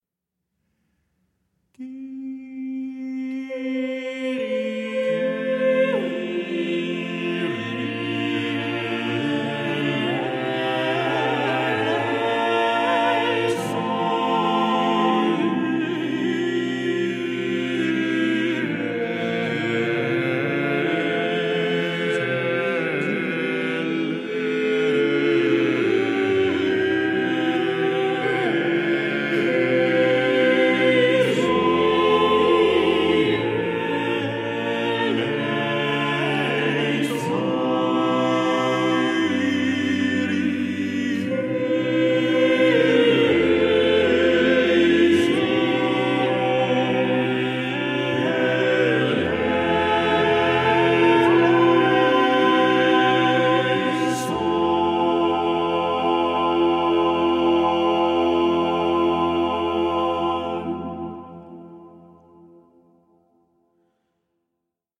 eight-voice